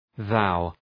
Προφορά
{ðaʋ}